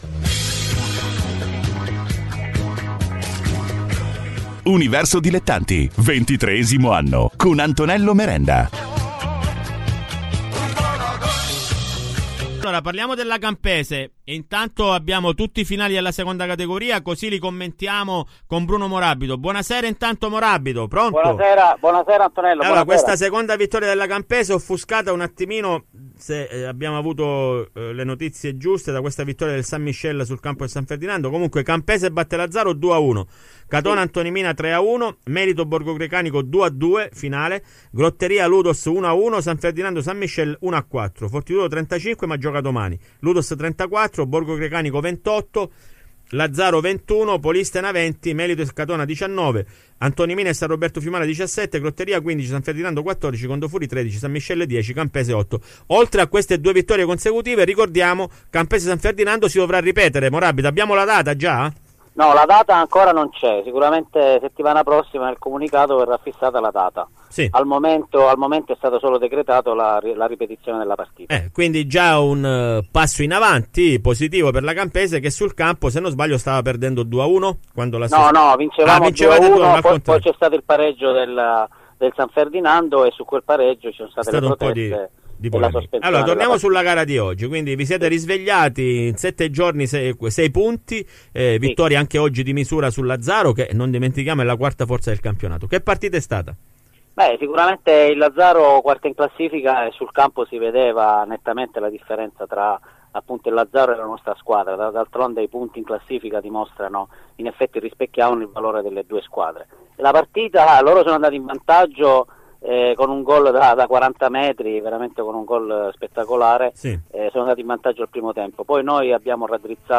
Le interviste ai protagonisti di Universo Dilettanti